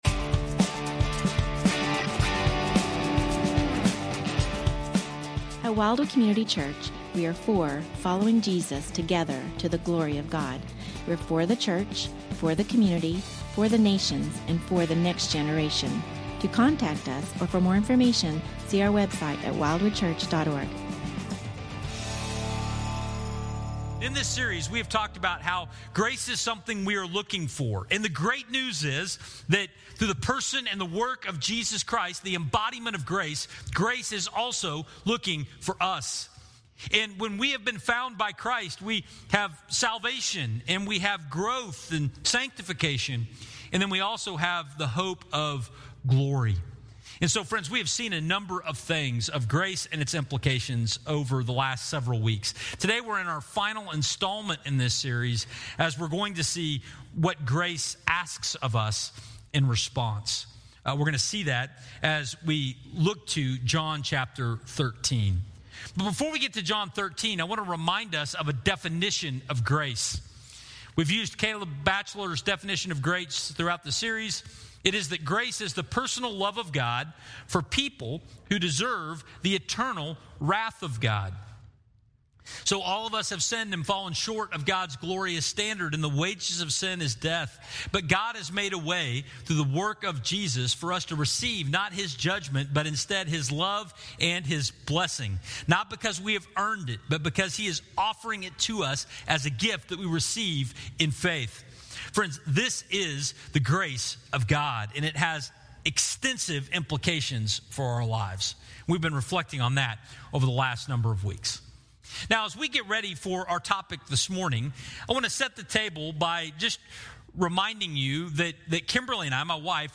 Trial to Triumph (part 2) Sermon Audio, Video, & Questions